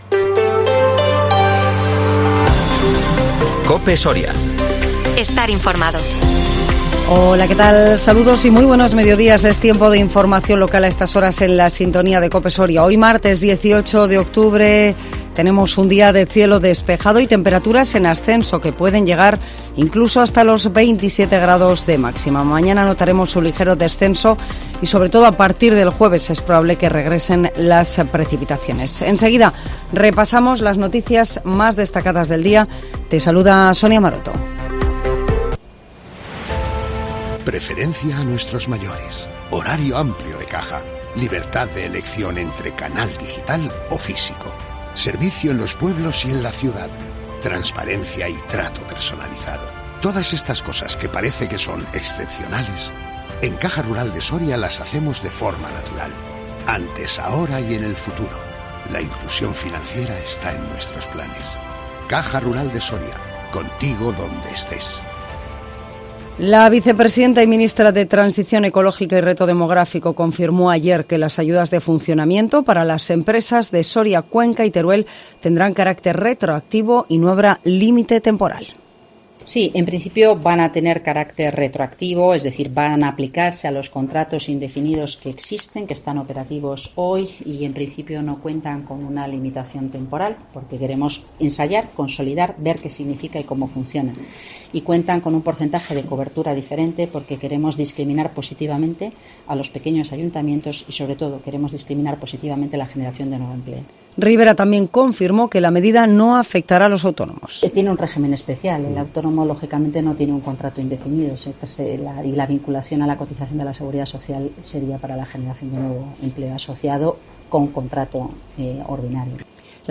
INFORMATIVO MEDIODÍA COPE SORIA 18 OCTUBRE 2022